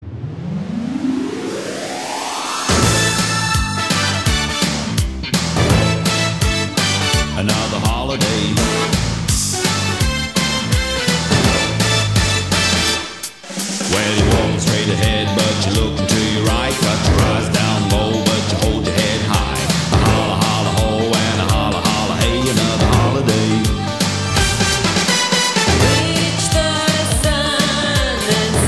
Gattung: Moderner Einzeltitel
Besetzung: Blasorchester
...einfach kultig und mit tollen Bläsersätzen...
Tonart: c-Moll und C-Dur